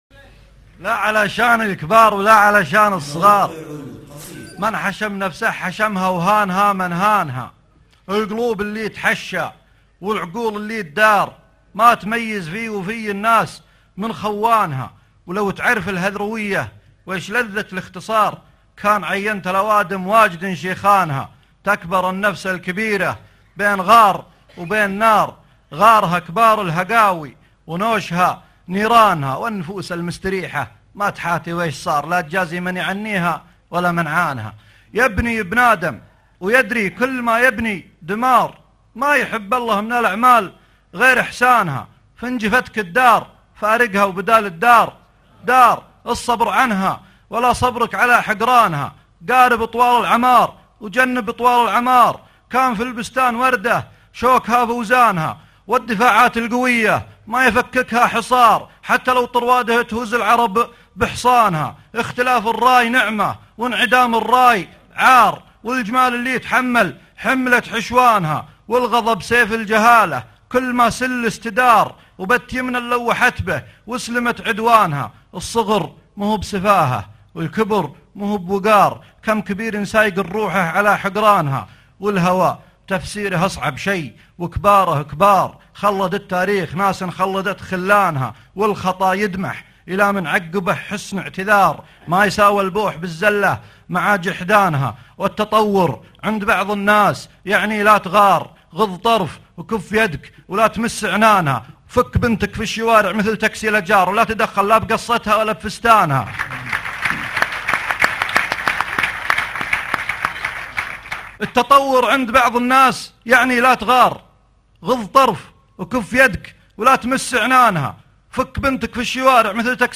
تجــربتــي الشخصــيه - مهرجان أهل القصيد السادس 2011   30 نوفمبر 2011